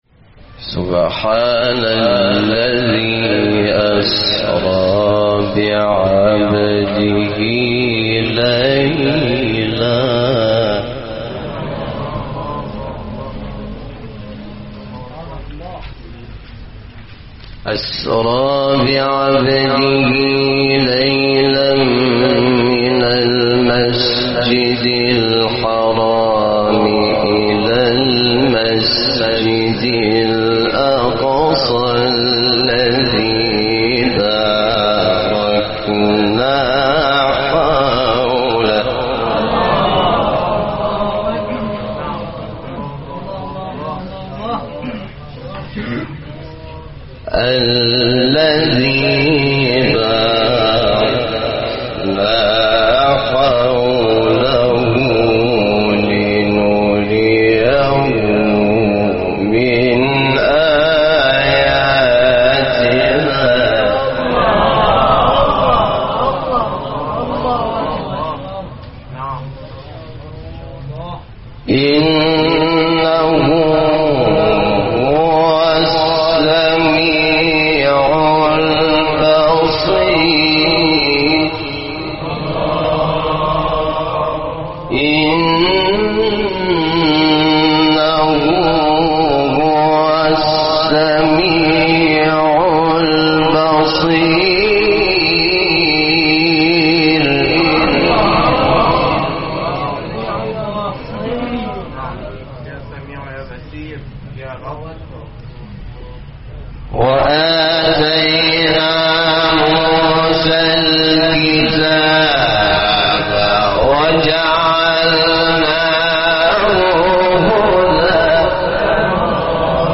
تلاوت سوره اسراء 1-14 استاد حامد شاکرنژاد | نغمات قرآن | دانلود تلاوت قرآن